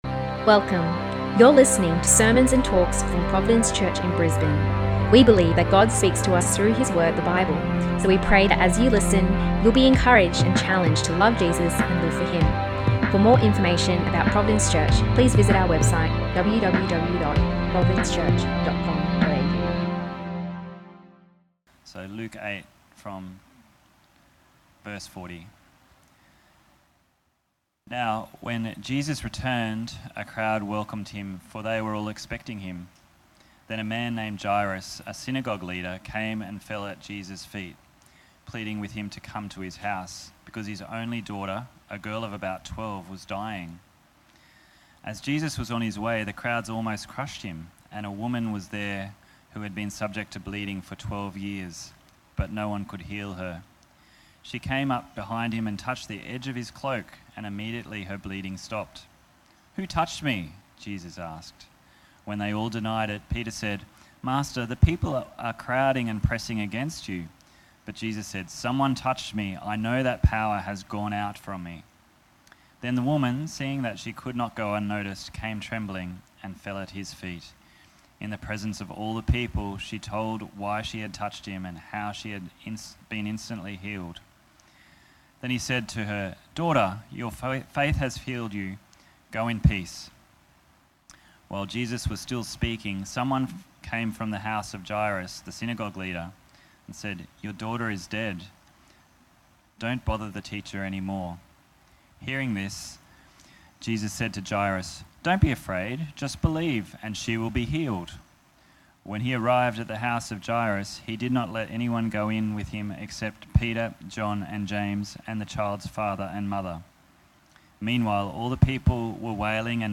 Luke-8-sermon.mp3